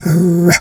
Animal_Impersonations
dog_2_growl_03.wav